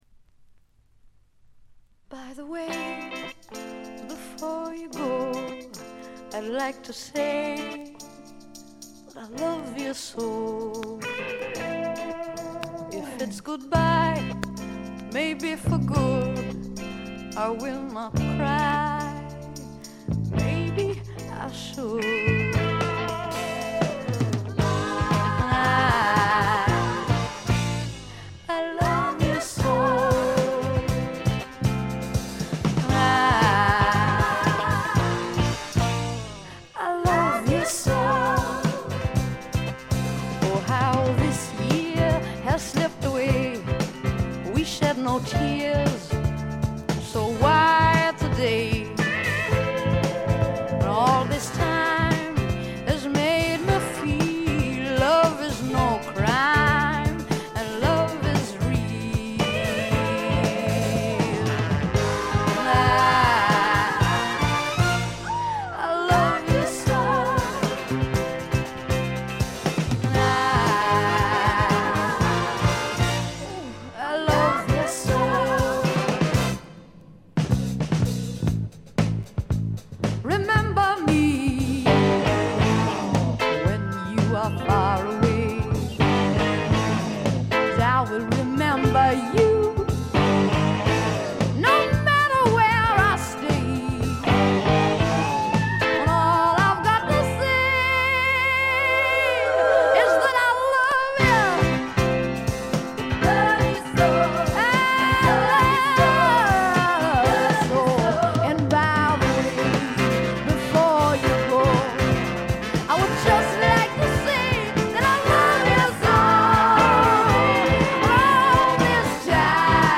A4序盤3連のプツ音。
サウンドは時代なりにポップになりましたが可愛らしい歌声は相変わらずなのでおじさんはご安心ください。
クラブ人気も高い女性ポップの快作です。
試聴曲は現品からの取り込み音源です。